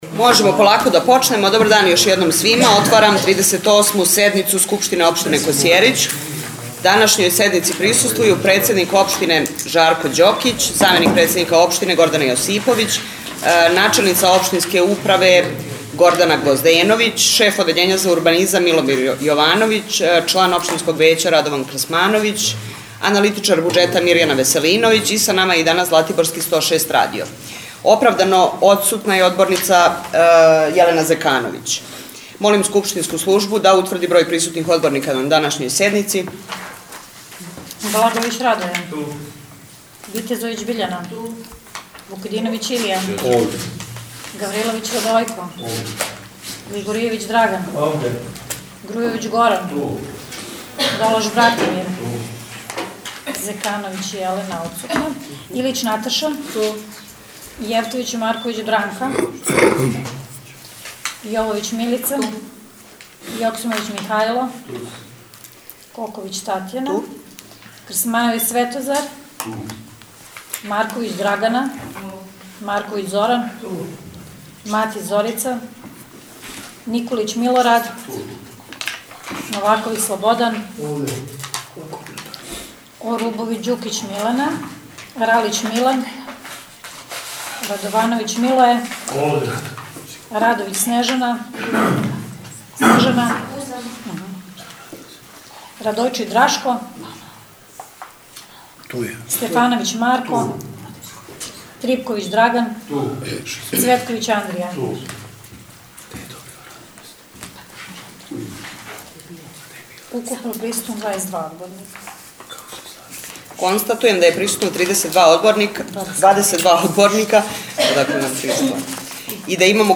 38. седница СО Косјерић